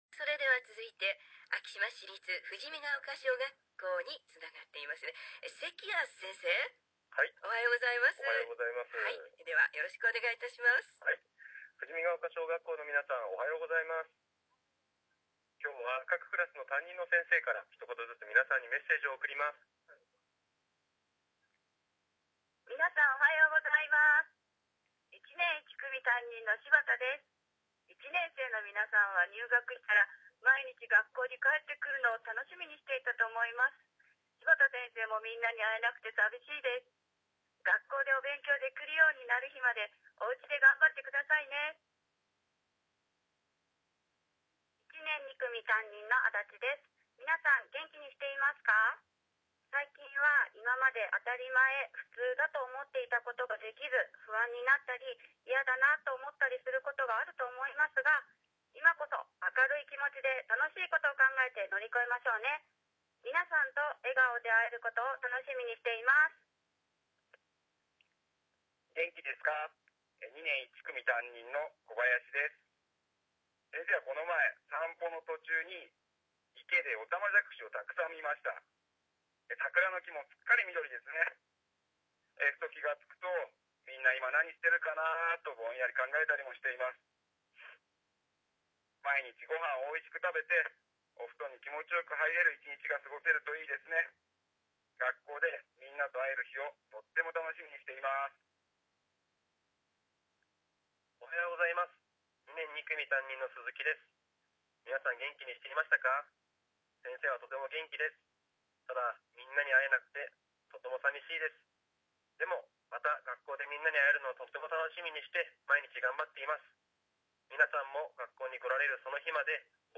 ＦＭラジオたちかわの企画により、今日から、市内全小中学校の先生方の生の声が、ラジオから放送されることになりました。